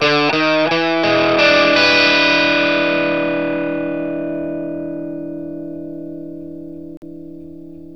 PICK1 E 7 60.wav